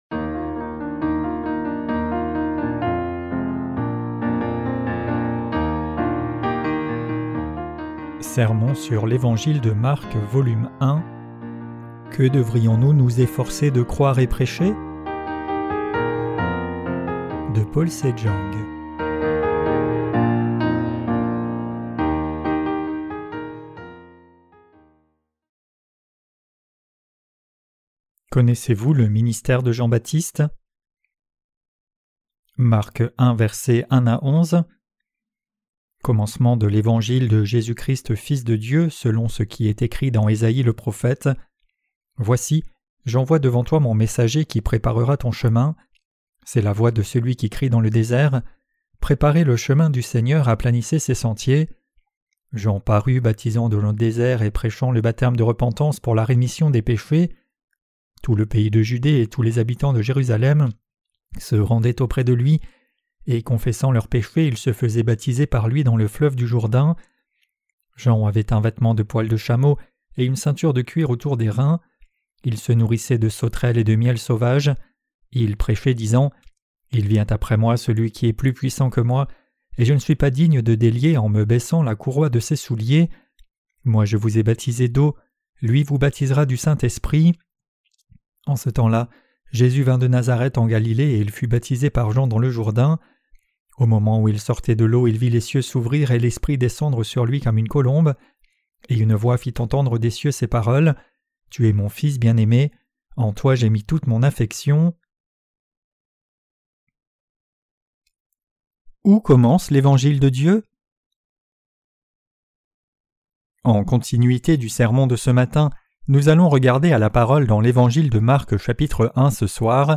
Sermons sur l’Evangile de Marc (Ⅰ) - QUE DEVRIONS-NOUS NOUS EFFORCER DE CROIRE ET PRÊCHER? 1.